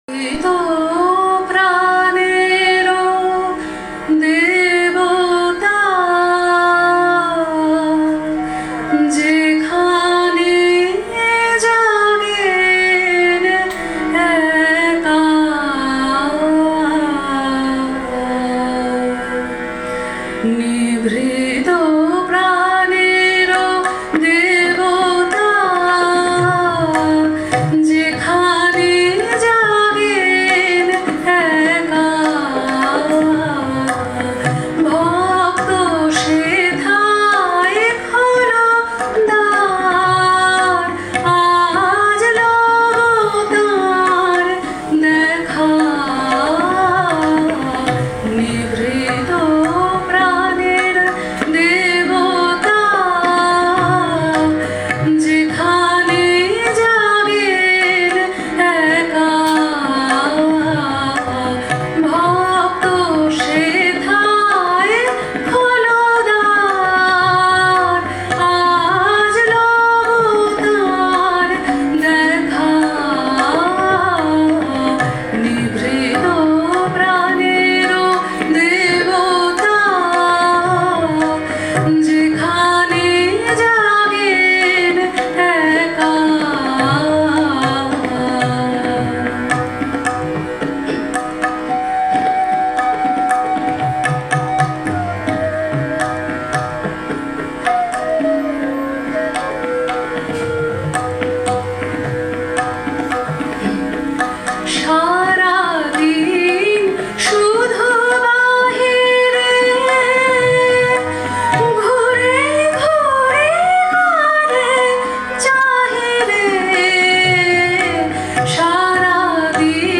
తబలా
వేణువు
రవీంద్ర సంగీత్
‘Nibhrito praner..’ Tagore song